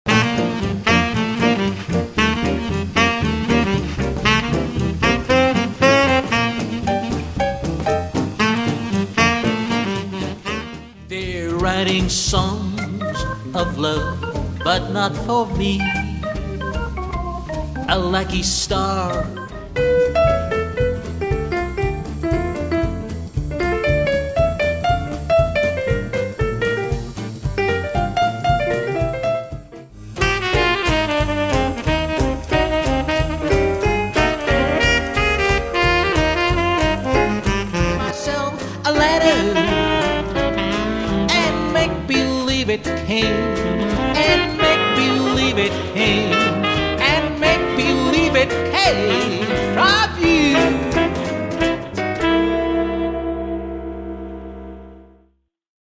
sextette de style Jazz Swing